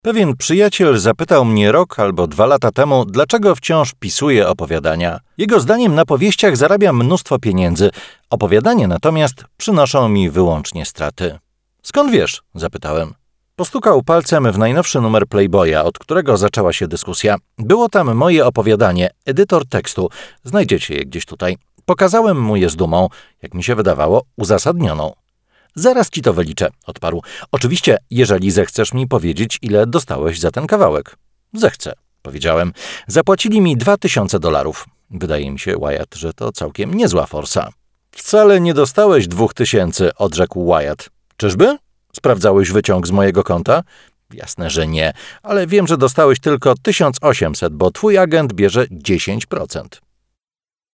Male 30-50 lat
Sounds excellent as a narrator, in telecom systems and e-learning courses.
Audiobook